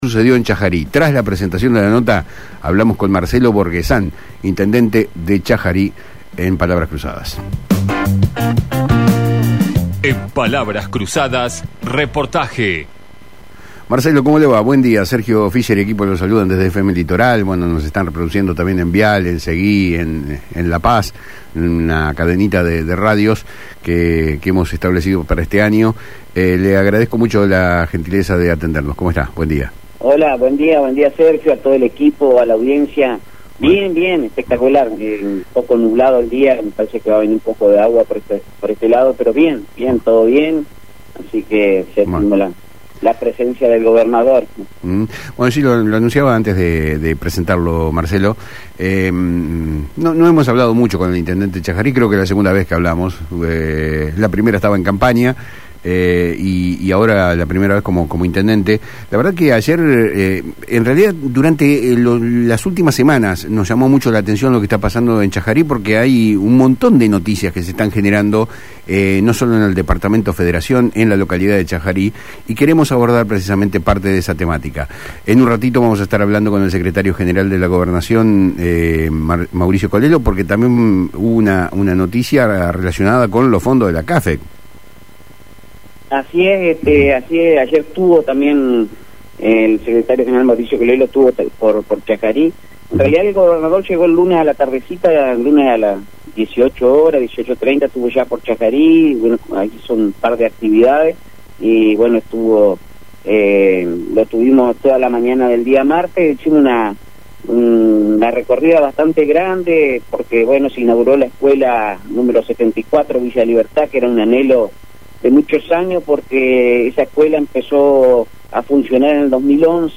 El intendente de Chajarí, Marcelo Borghesan, en diálogo con Palabras Cruzadas de FM Litoral, brindó detalles sobre la reciente visita del gobernador de la provincia, Rogelio Frigerio, y gran parte de su gabinete, destacando importantes inauguraciones y avances en infraestructura y desarrollo energético.